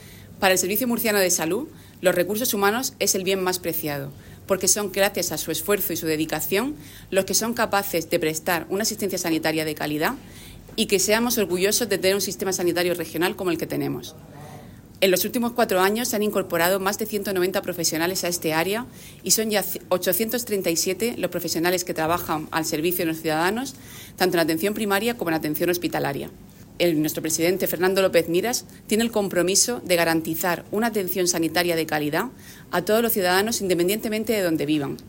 Declaraciones de la gerente del Servicio Murciano de Salud (SMS), Isabel Ayala, sobre los recursos humanos de este organismo, en el acto con motivo del 40 aniversario del hospital de Yecla.